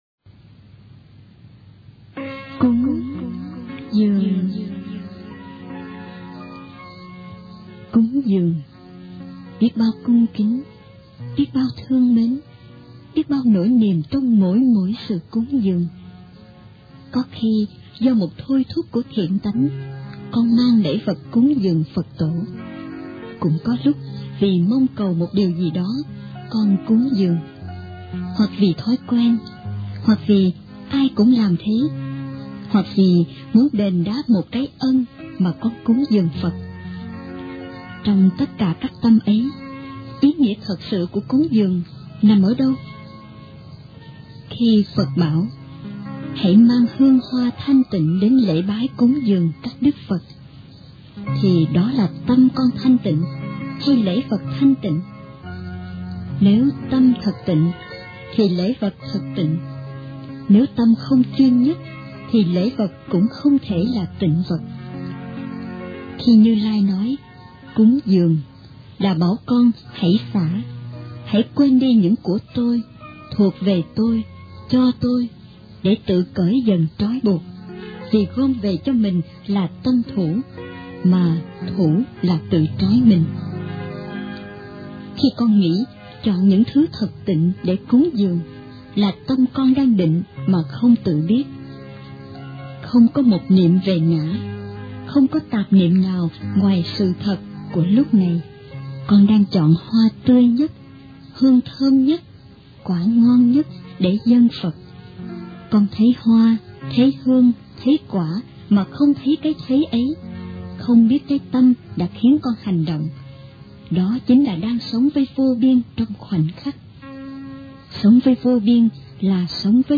Truyện Đọc Truyện Phật Giáo Nói Về Triết Lý Sống